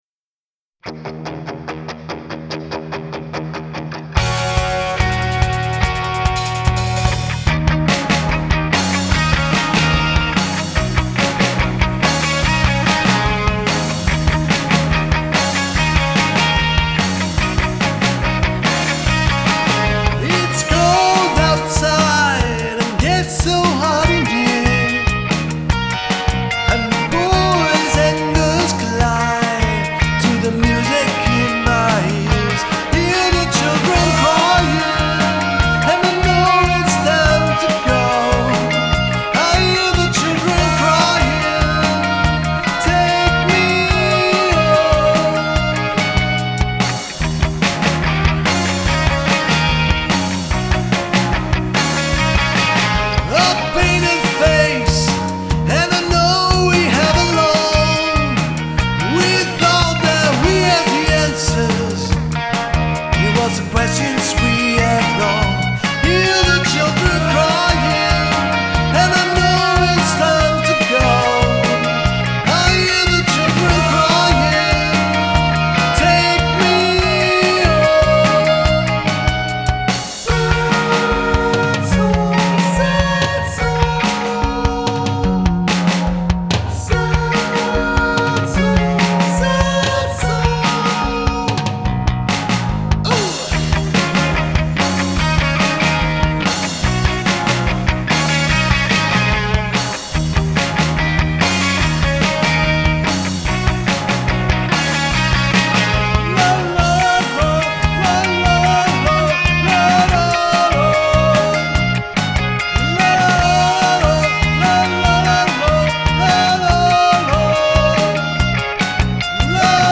Bass: Warmoth Jazz Bass on an Ampeg SVT450 AMP.
Drums: Roland TD20
Microphone: Rode NT2